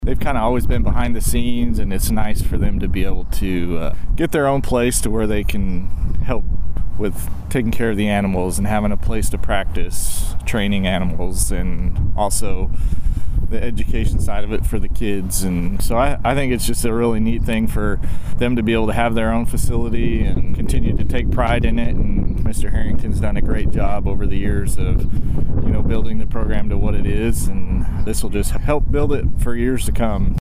Nowata Public Schools held a groundbreaking ceremony on Friday morning off Lenapah Drive for a new agriculture building.
Nowata Board of Education President Kurt Bashford discussed the benefits of the facility.